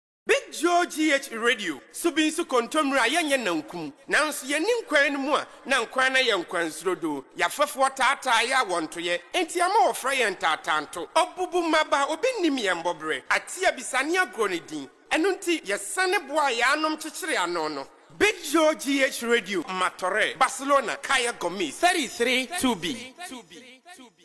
Identificació de la ràdio amb esment a Martorell, en llengua Akan